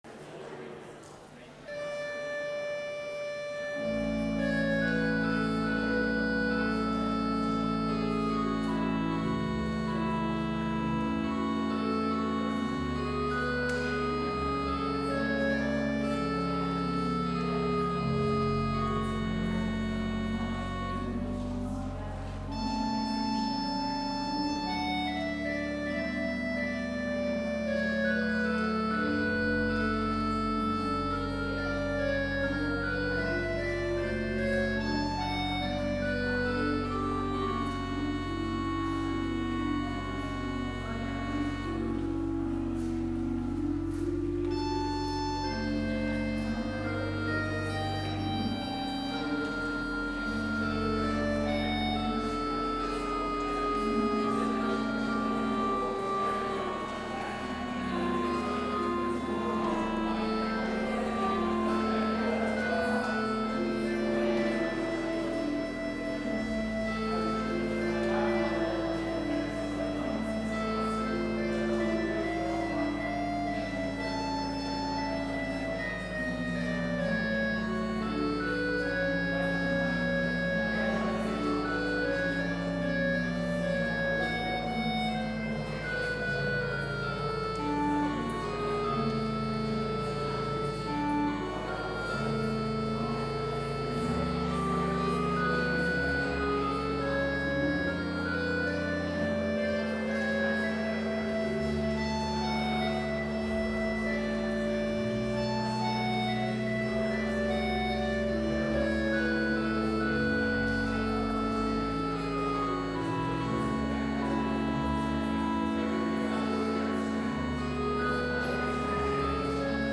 Complete service audio for Chapel - January 28, 2020